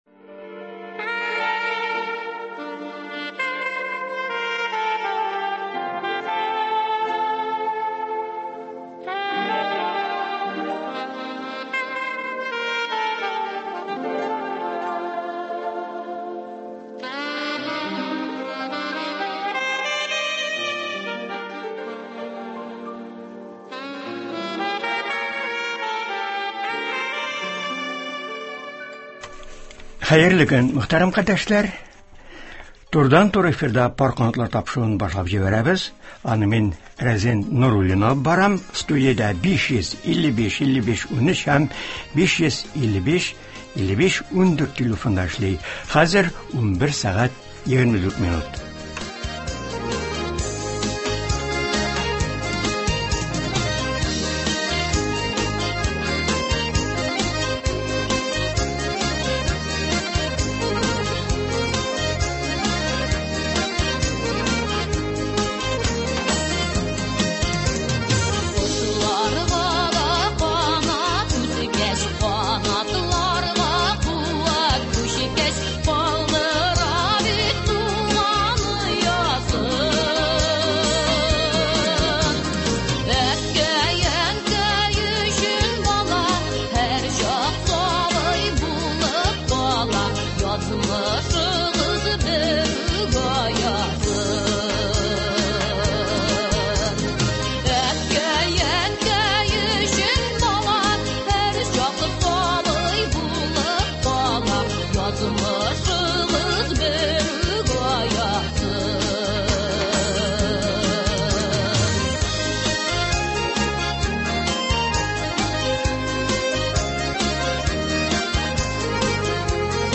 “Сөембикә” журналы гаиләдә балаларны тәрбияләү мәсьәләсенә зур игътибар бирә, 1 июньдә Халыкара балаларны яклау көне булуын һәм укучы балаларның җәйге каникулы башлануын күздә тотып, җәйге чорда журнал эчтәлегенә шушы темаларны кертә. Болар хакында турыдан-туры эфирда редакция хезмәткәрләре сөйләячәкләр һәм тыңлаучылар сорауларына җавап бирәчәкләр.